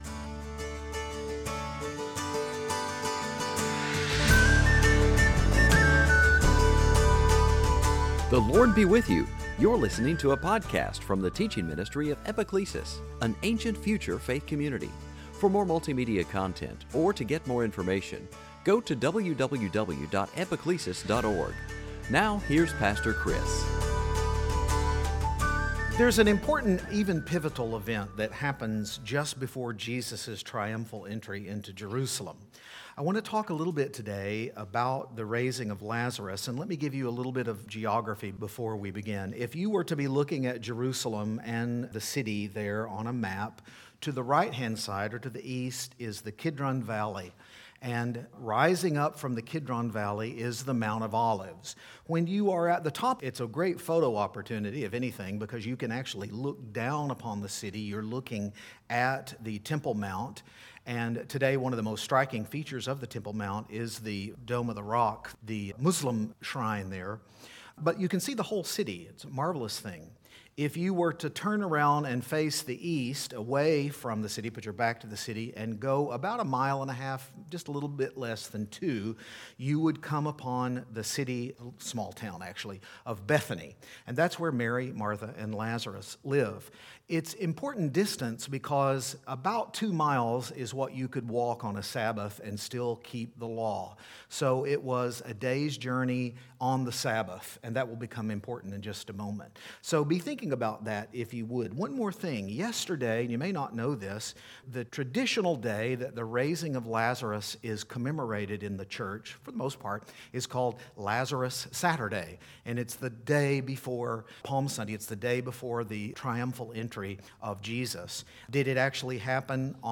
Service Type: Palm Sunday